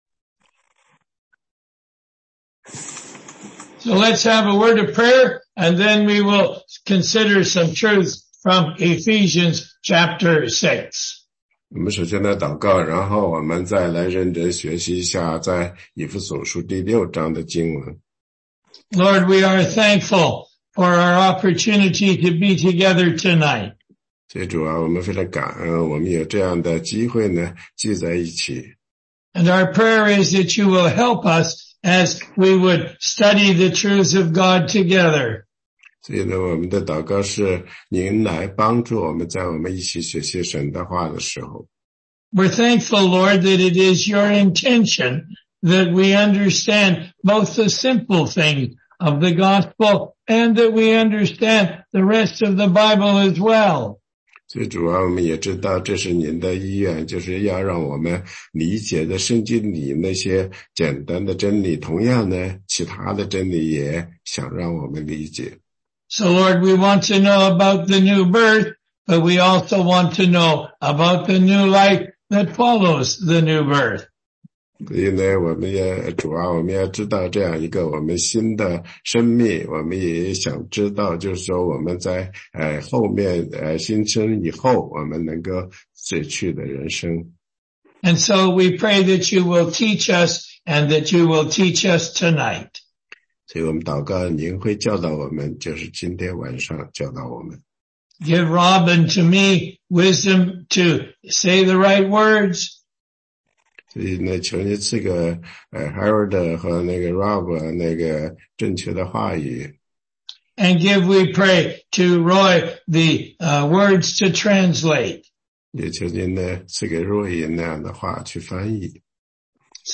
16街讲道录音 - 福音基础